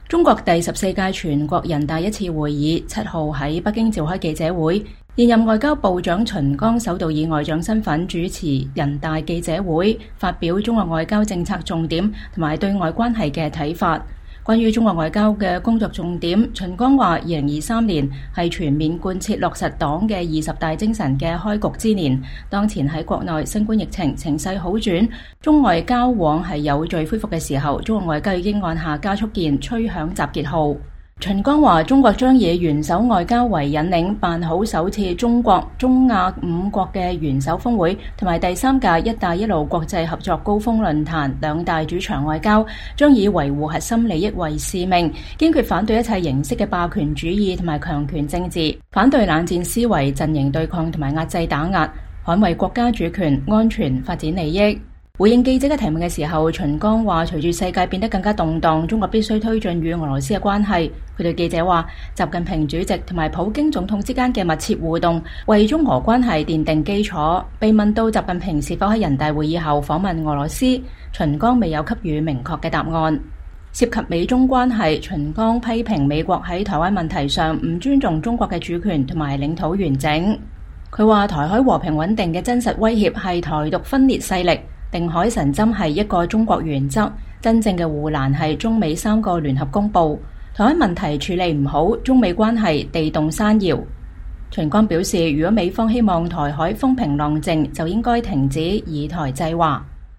中國外交部長秦剛2023年3月7日出席人大記者會。